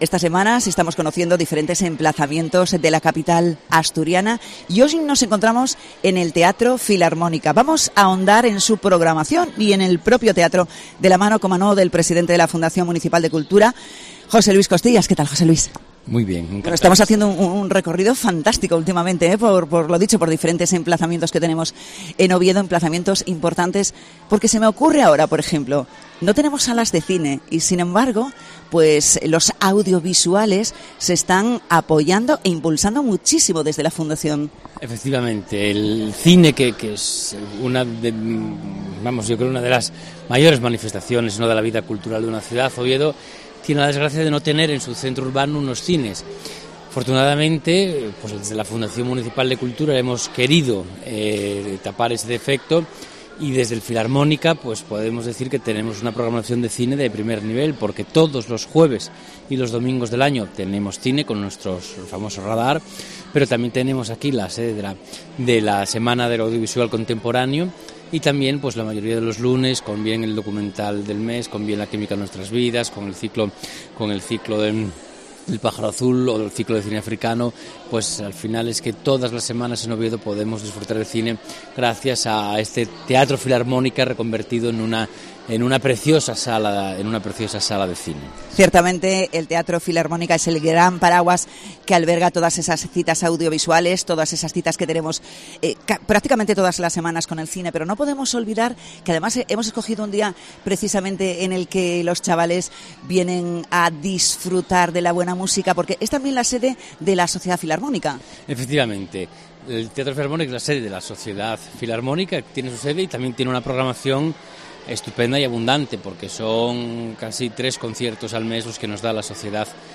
Entrevista al presidente de la Fundación Municipal de Cultura de Oviedo, José Luis Costillas